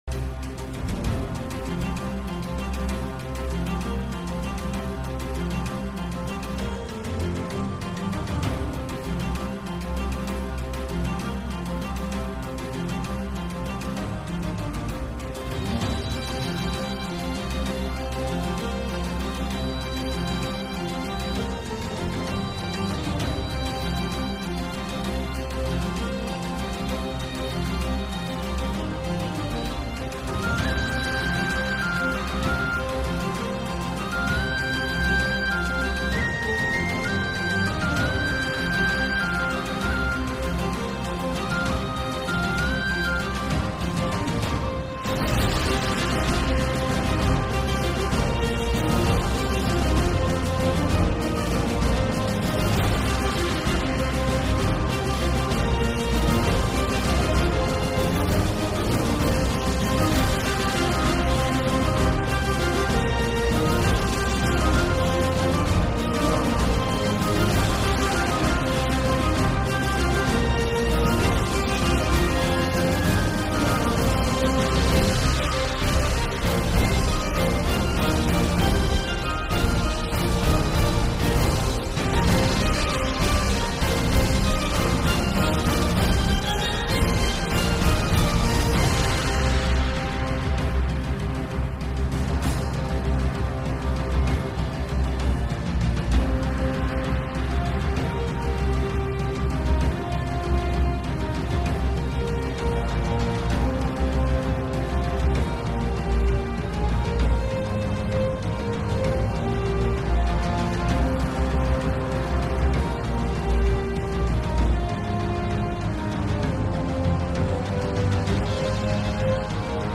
Música-de-Piratas│Música-de-aventuras-de-piratas-50k-1-.mp3
KFxv7YyKdCO_Música-de-Piratas│Música-de-aventuras-de-piratas-50k-1-.mp3